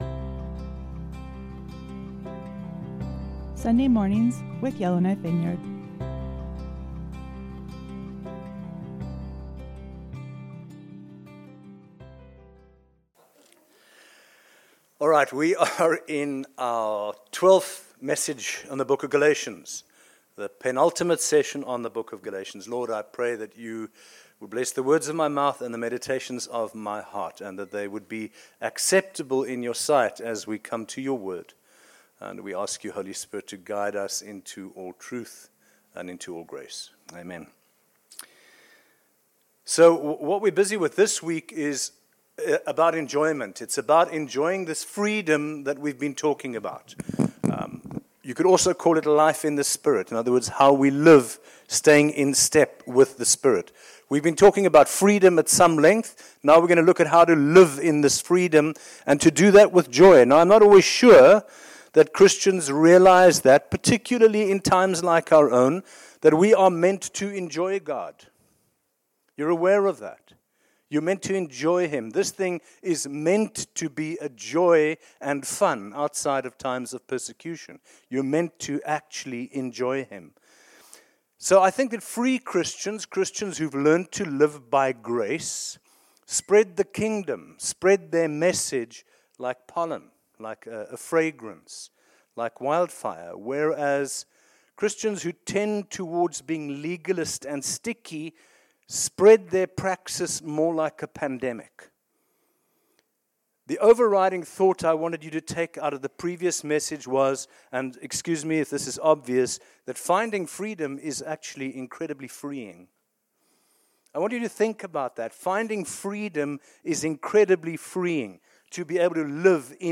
Sunday sermon August 22nd